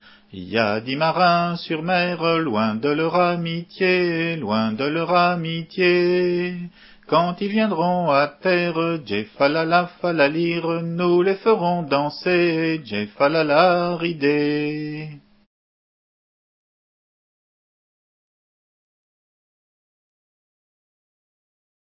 Entendu au festival des "Assemblées gallèses" en juillet 89